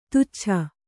♪ tuccha